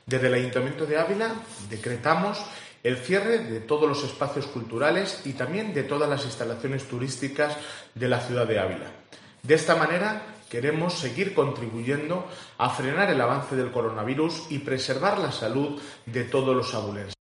Alcalde de Ávila, cierre de espacios culturales